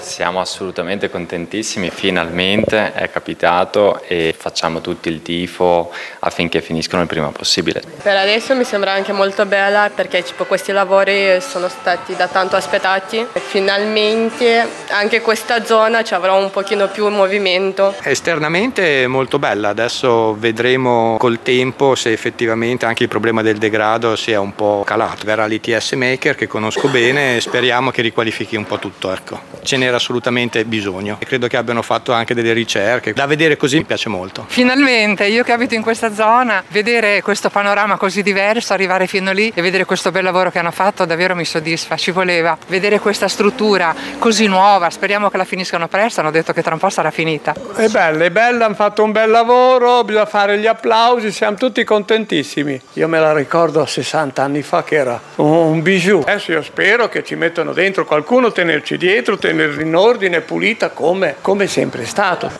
Le voci di residenti e commercianti: